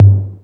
TR808TOM.wav